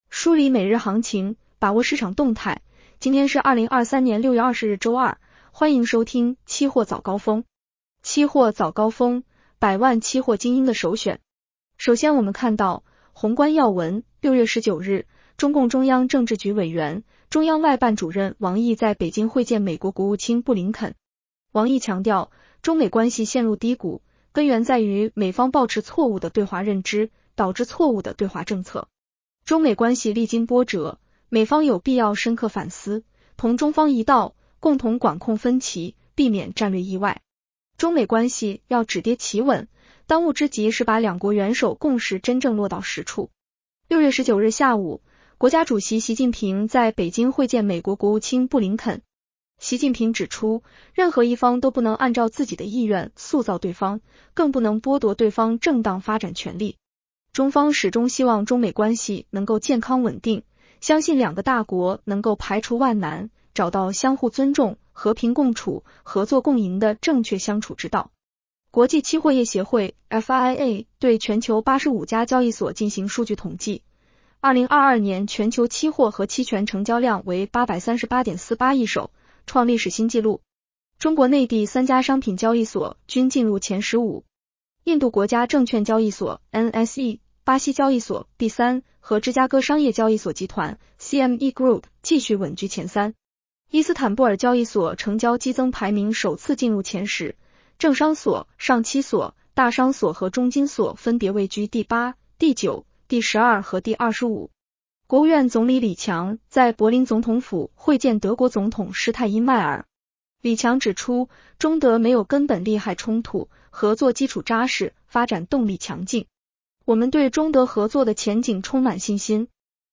【期货早高峰-音频版】 女声普通话版 下载mp3 宏观要闻 1. 6月19日，中共中央政治局委员、中央外办主任王毅在北京会见美国国务卿布林肯。